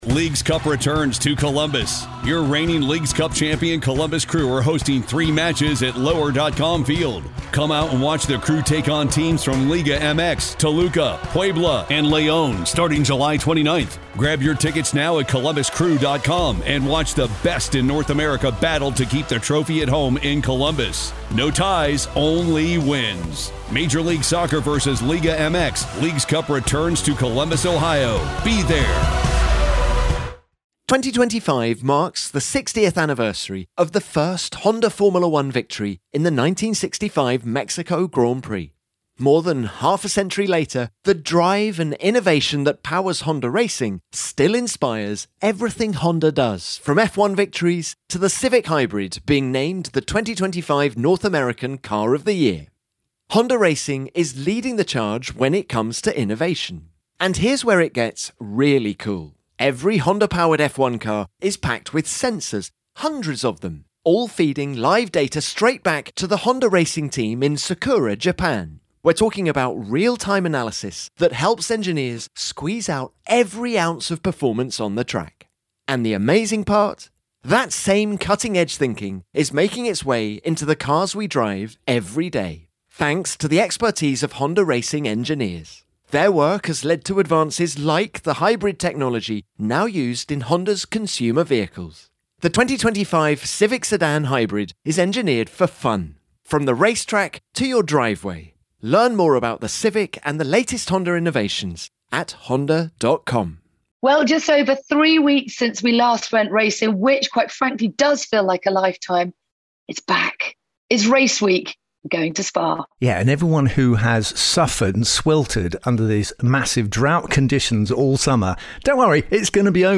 Natalie Pinkham, Tom Clarkson and Damon Hill are back after the summer break for an in-depth chat about how to go fast on the Spa Francorchamps circuit, what the future may hold for Daniel Ricciardo, and the new F1 rule change which might shake up the order.